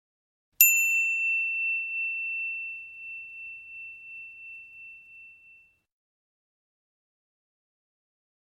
Notification Sound Download